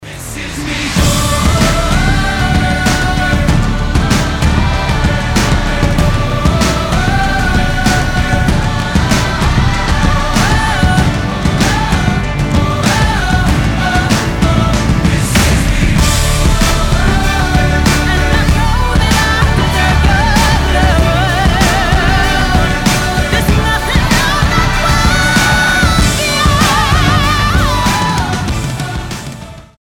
• Качество: 320, Stereo
поп
громкие
женский вокал
хор
Мюзикл
саундтрек